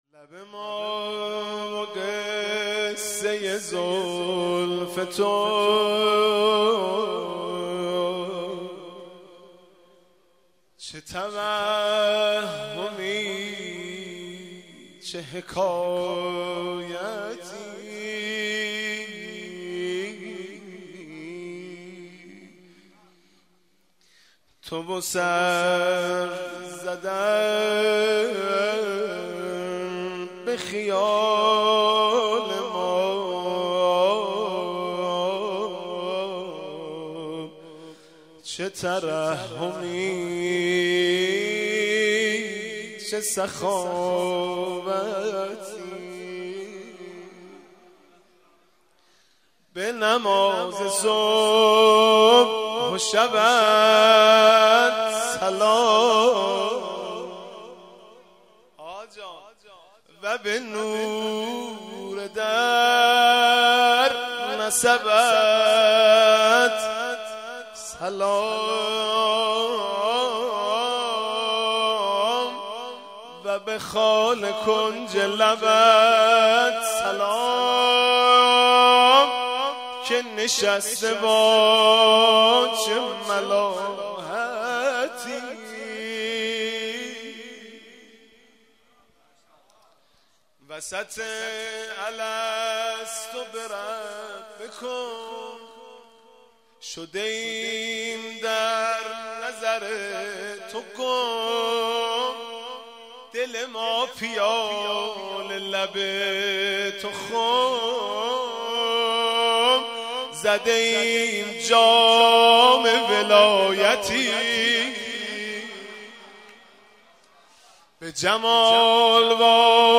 (مدح)